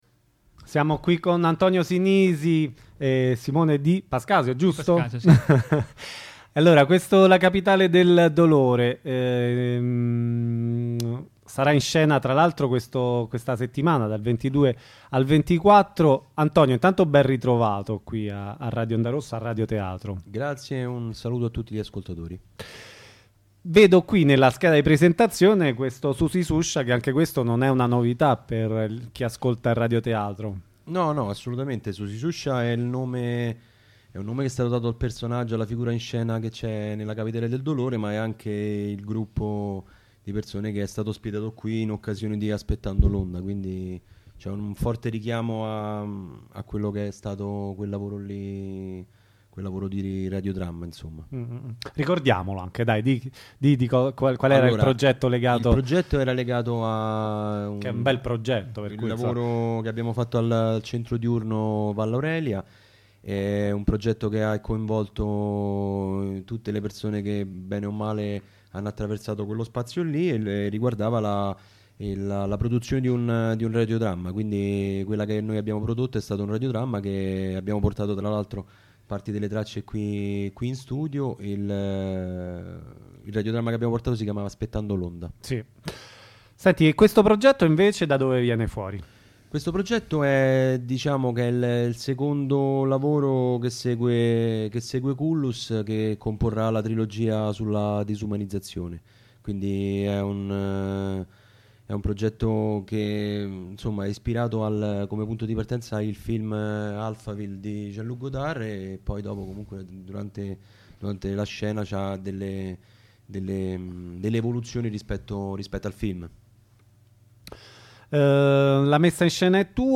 intervista.lacapitaledeldolore.mp3